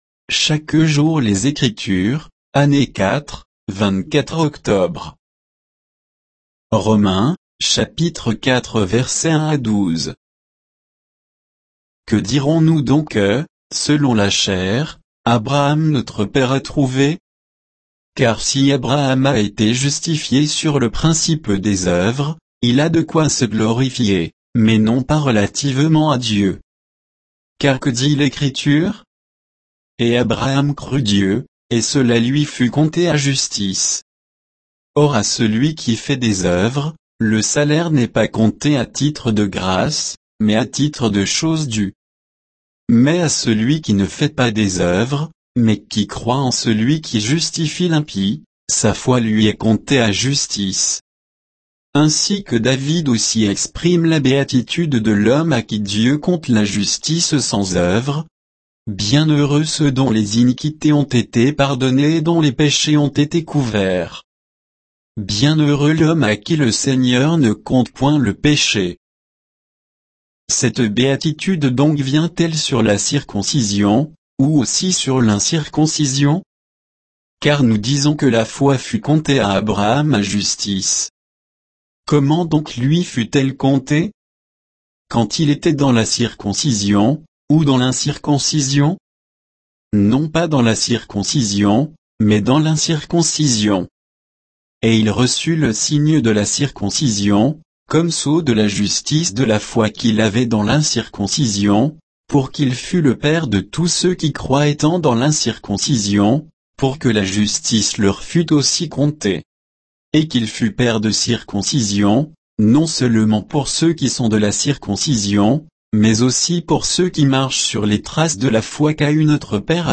Méditation quoditienne de Chaque jour les Écritures sur Romains 4